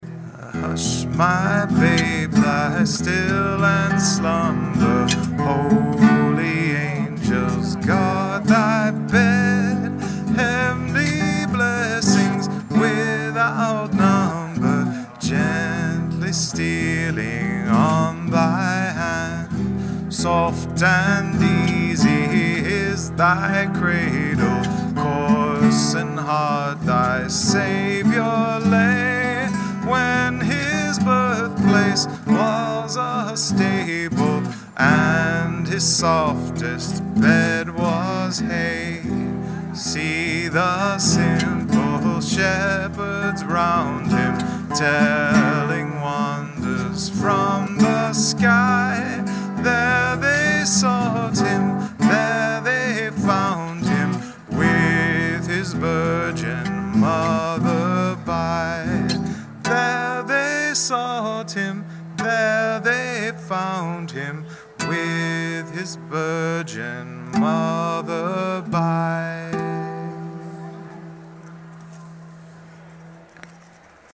same tour at the Cumberland Mall in Vineland, NJ:
Voice and Guitar- Cumberland Mall Vineland, NJ December 15, 2012